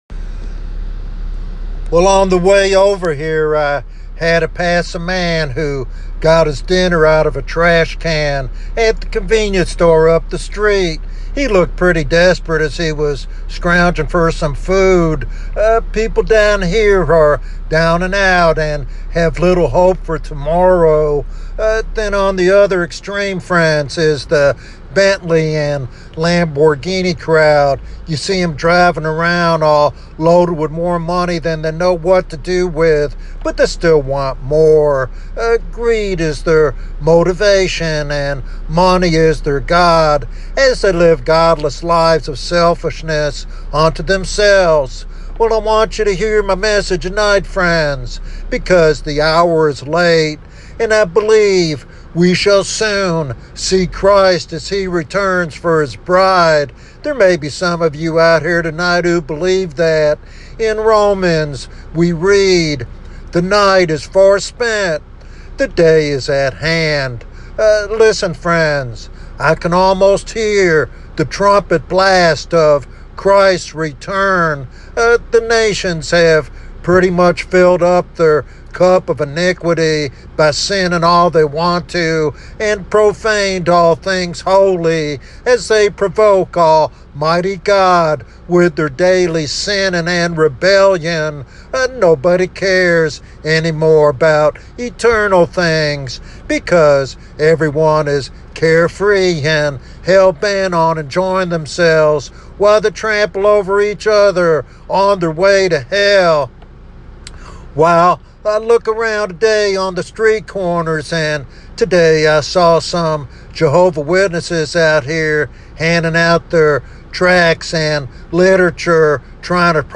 This sermon challenges believers to examine their readiness and calls unbelievers to embrace salvation before it is too late.